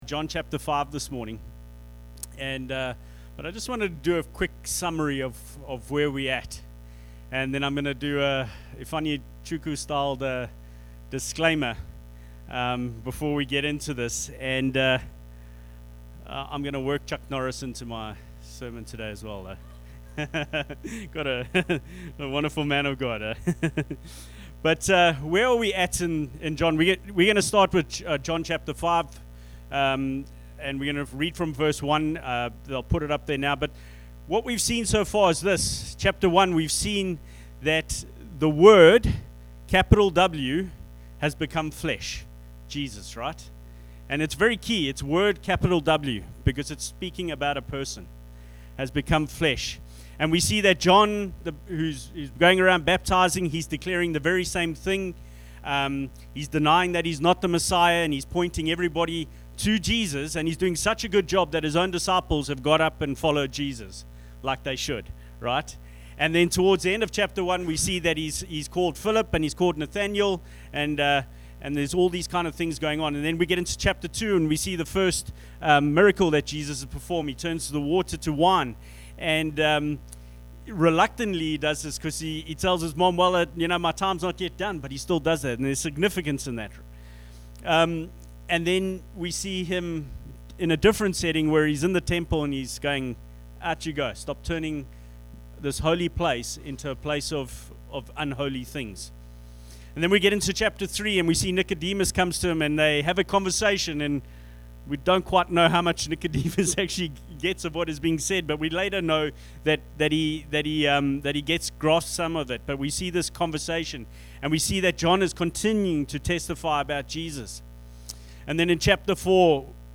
Download Download The Gospel of John Current Sermon Healing at the Pool.